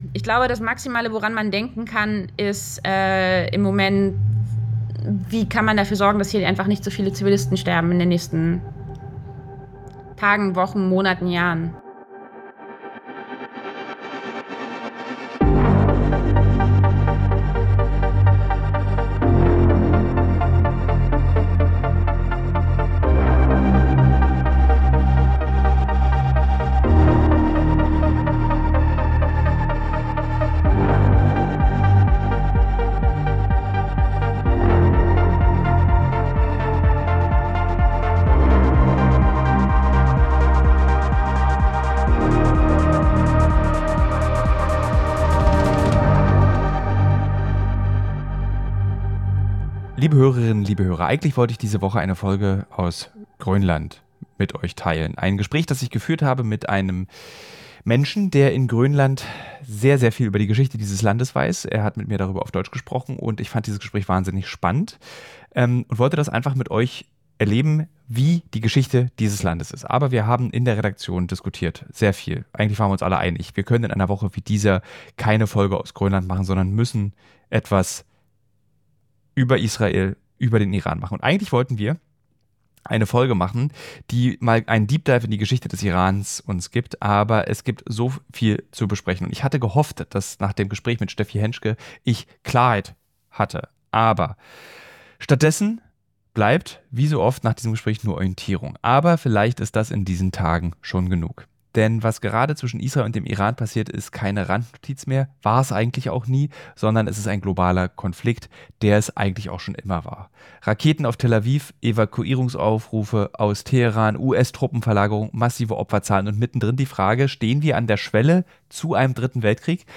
Sie berichtet aus Tel Aviv – und sie ist vor allem eins: müde.